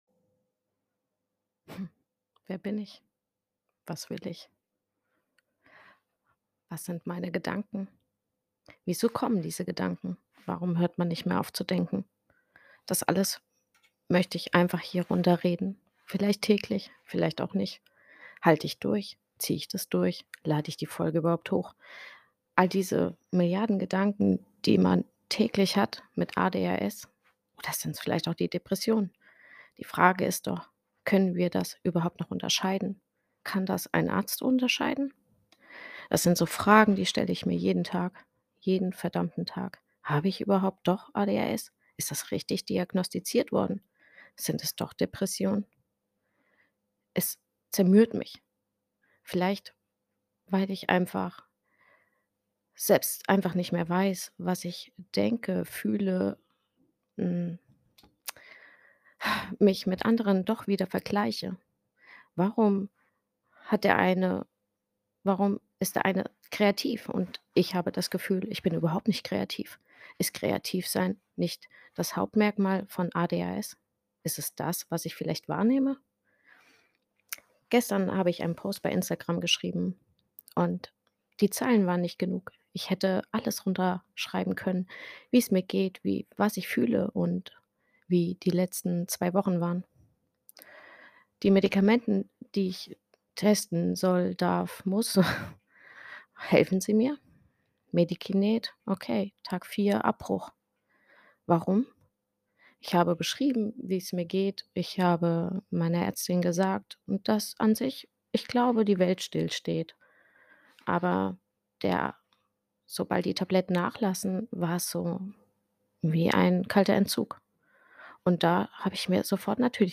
Es ist wie eine Art Tagebuch Podcast .